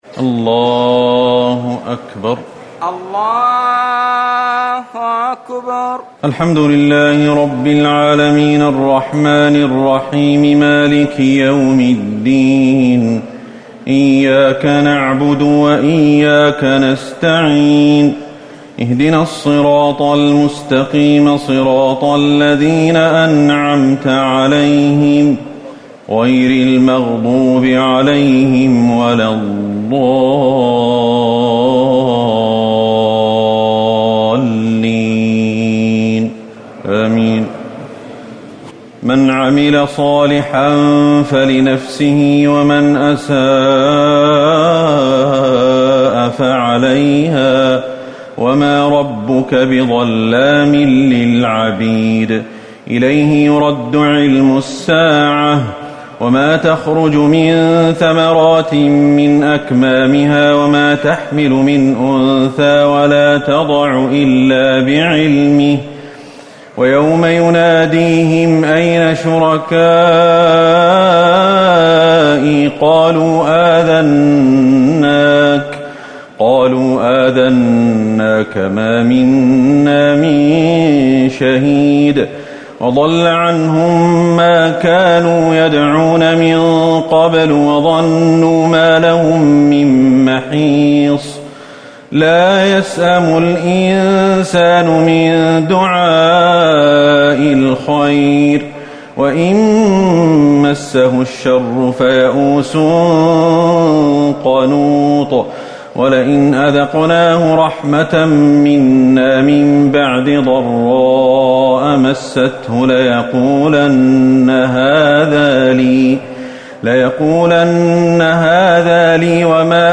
تراويح ٢٤ رمضان ١٤٤٠ من سورة فصلت ٤٦ - الزخرف ٢٥ > رمضان 1440هـ > التراويح